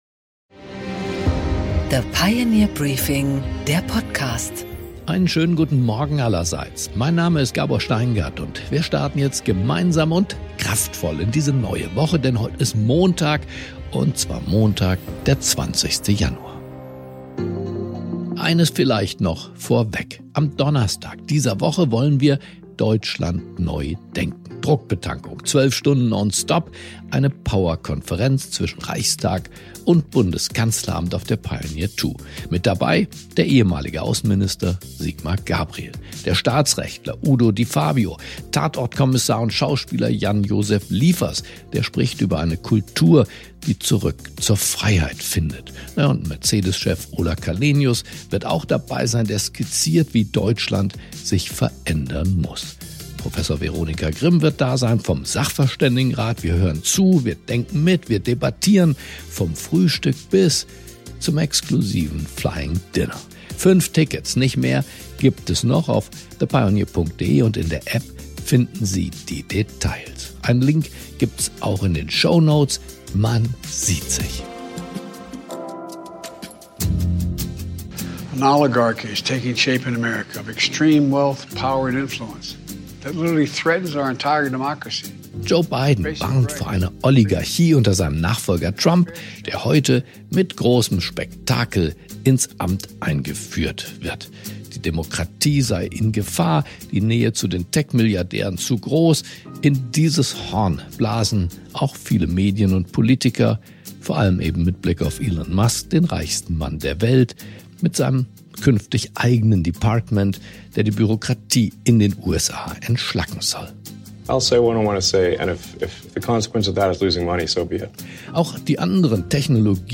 Gabor Steingart präsentiert das Pioneer Briefing
Interview